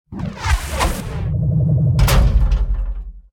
salvage2.ogg